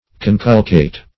Search Result for " conculcate" : The Collaborative International Dictionary of English v.0.48: Conculcate \Con*cul"cate\, v. t. [imp.